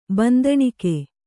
♪ bandaṇike